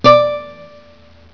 ring.WAV